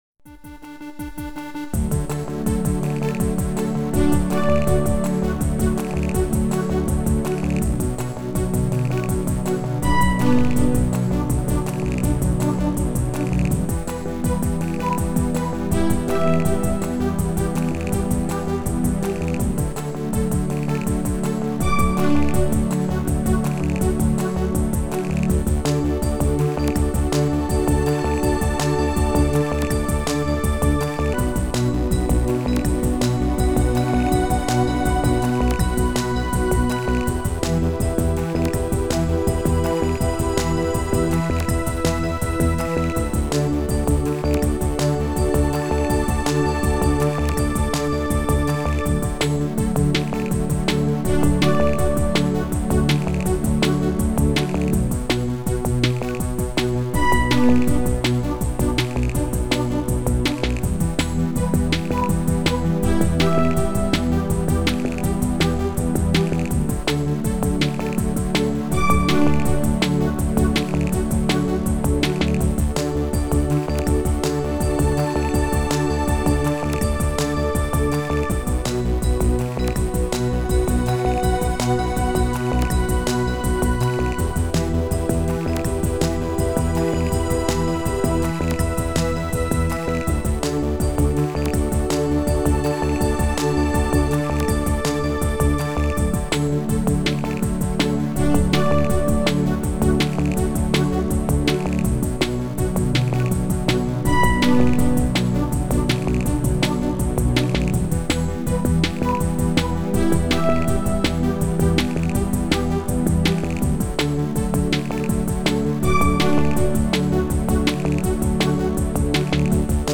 Cosmic Equinox Music